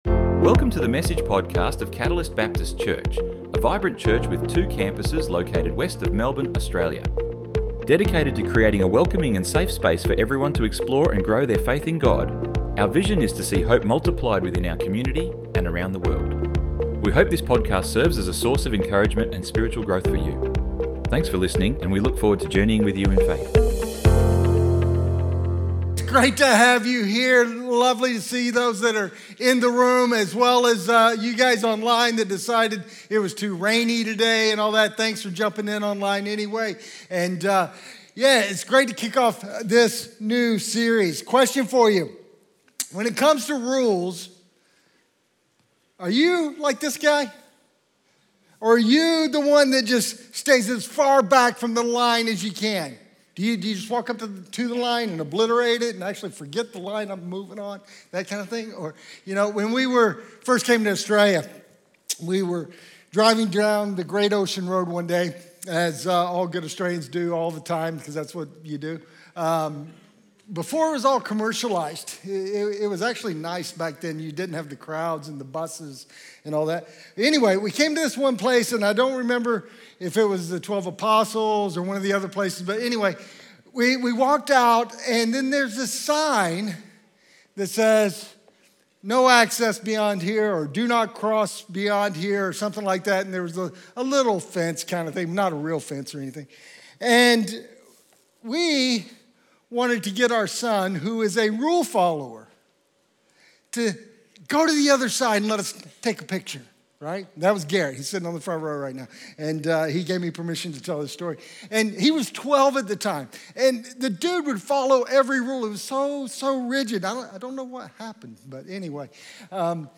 Download Download Sermon Notes 01-Jesus-said-WHAT-Anger-to-Reconciliation.pdf 01 - online notes Jesus said WHAT - Anger Kills.doc When was the last time you got angry?